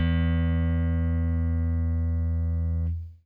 FENDERSFT AA.wav